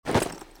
stand.wav